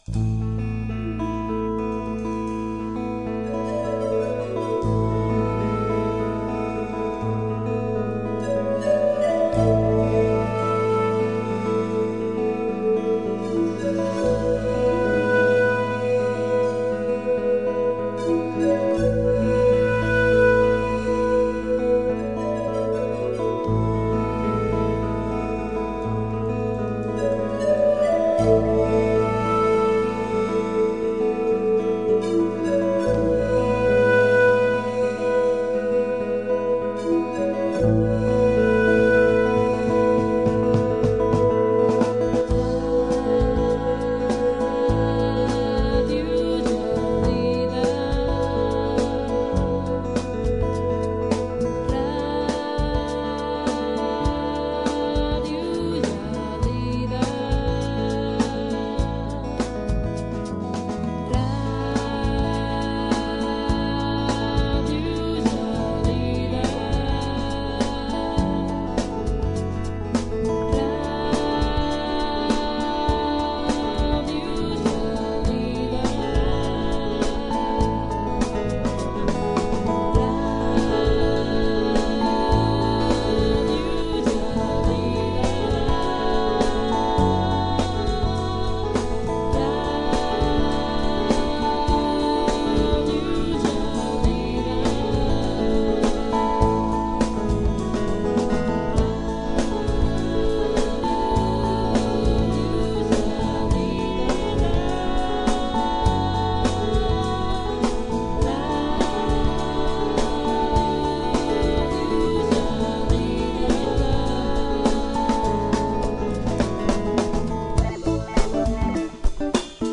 Indicatiu musical llarg de l'emissora